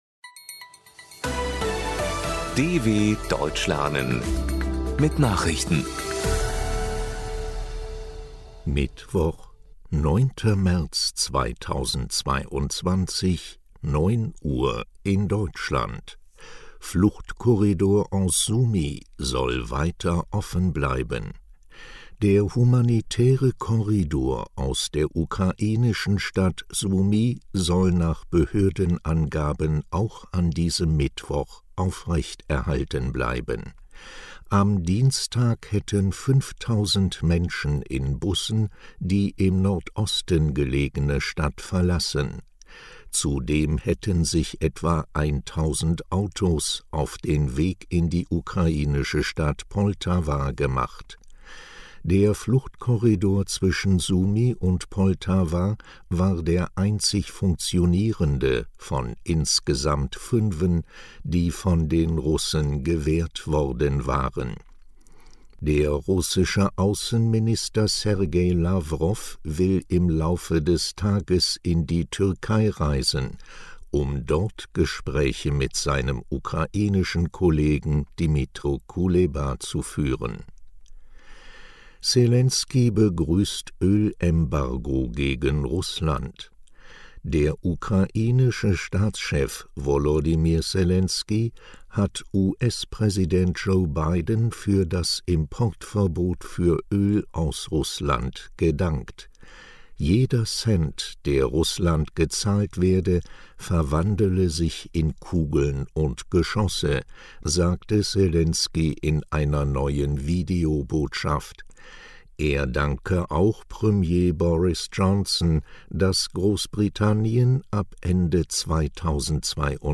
09.03.2022 – Langsam gesprochene Nachrichten
Trainiere dein Hörverstehen mit den Nachrichten der Deutschen Welle von Mittwoch – als Text und als verständlich gesprochene Audio-Datei.